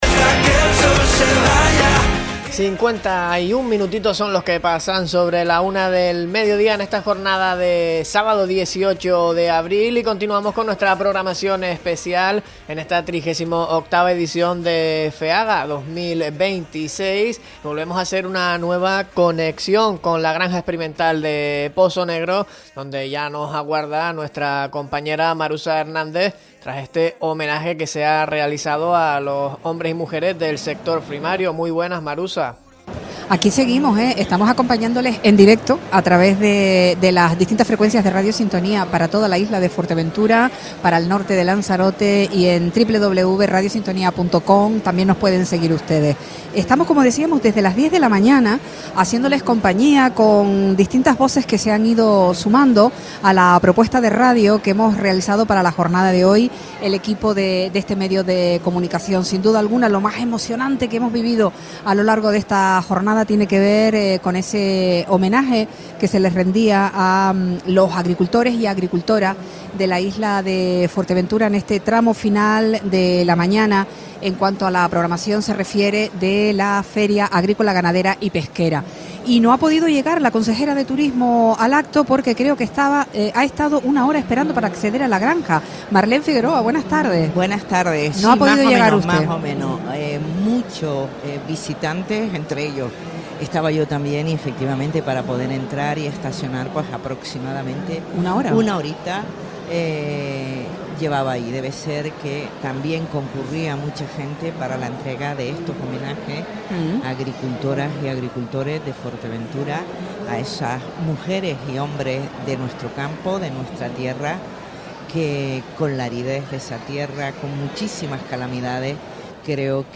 Marlene Figueroa en el set de Radio Sintonía en Feaga 2026 - Radio Sintonía
Visita de la consejera de Turismo del Cabildo de Fuerteventura en el set de Radio Sintonía para charlar sobre el desarrollo de la feria en su 38ª edición Deja un comentario
Entrevistas